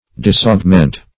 Disaugment \Dis`aug*ment"\, v. t.
disaugment.mp3